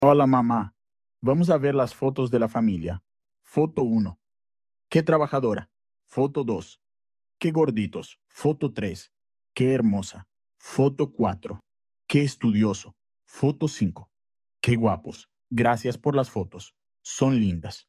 Listen as Juan David is reacting to his family photos.